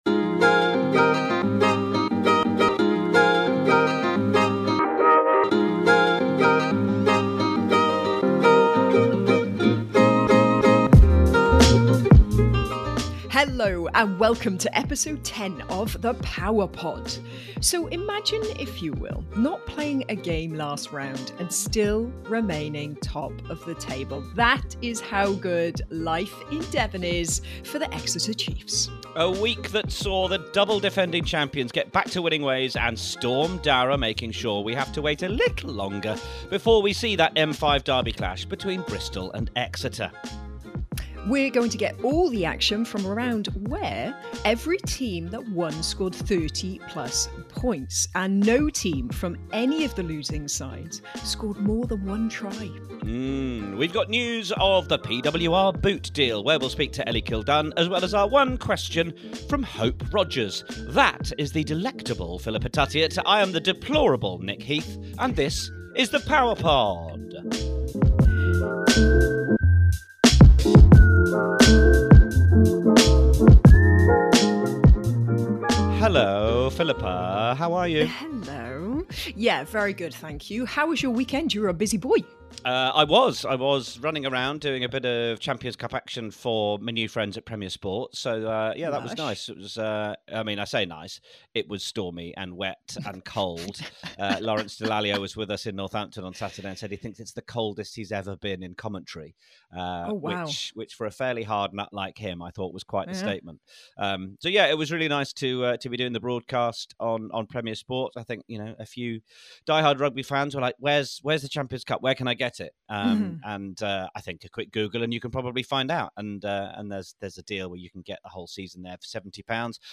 We've three guests via the Power Line, we hear Hope Rogers' answer to One Question and we find out why Philippa Tuttiett shouldn't reheat rice on her birthday.
The Power Pod is hosted by former Wales international rugby player Philippa Tuttiett and lead commentator Nick Heath.